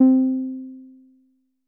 BASS1 C4.wav